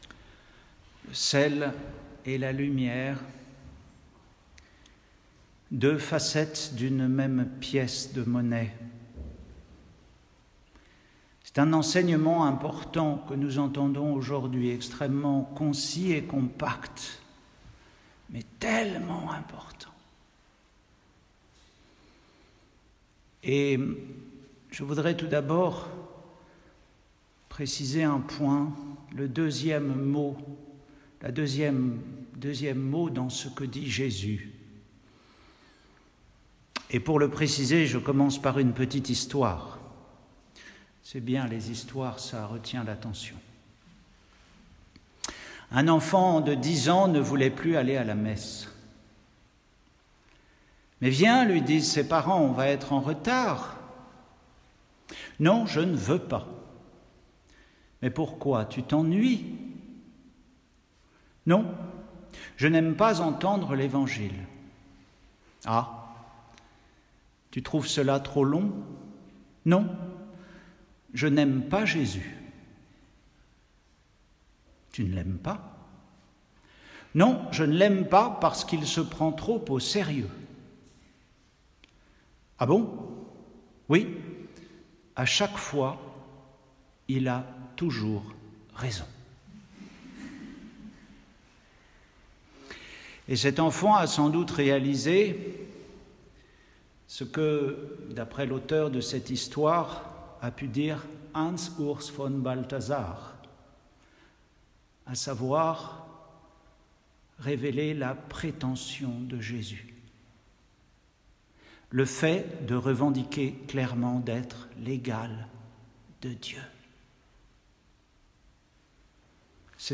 Retrouvez les méditations d’un moine sur les lectures de la messe du jour.